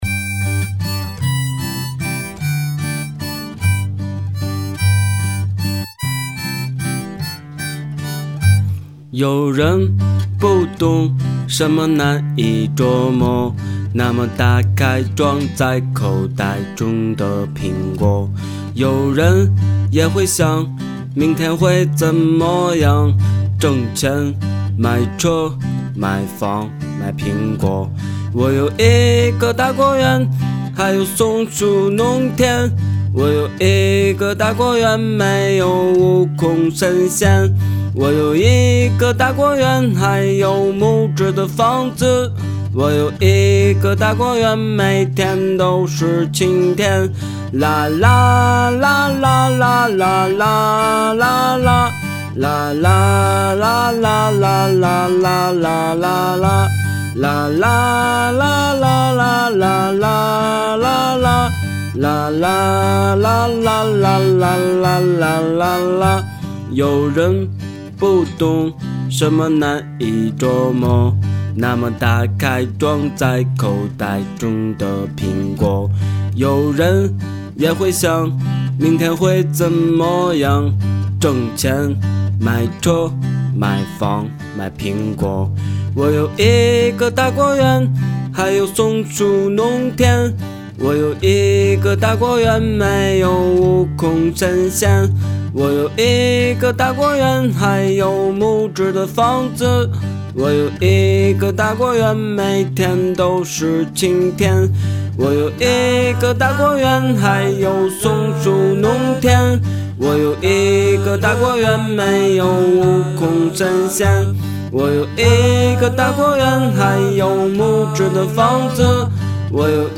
曲风：民谣